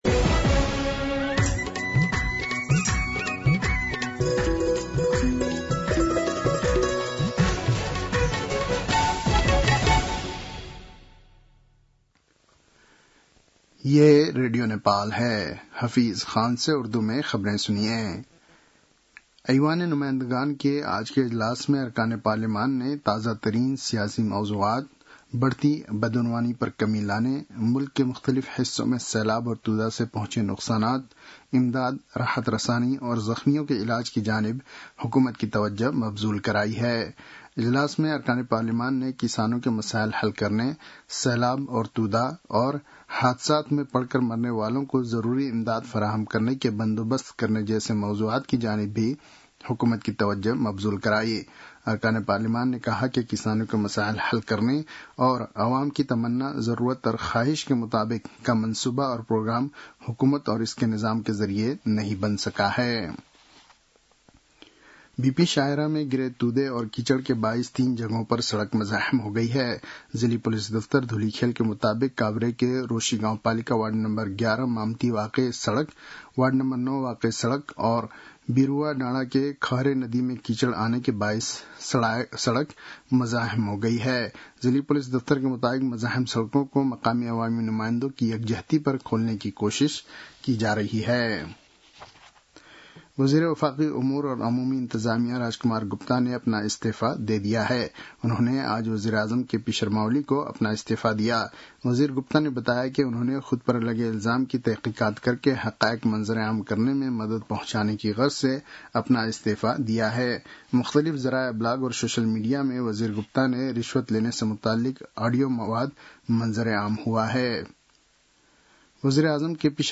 उर्दु भाषामा समाचार : ३१ असार , २०८२